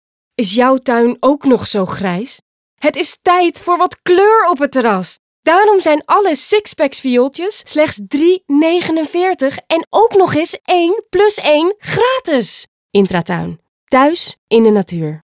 Voice Demo
gaite-jansen-demo---no19-talent-management.m4a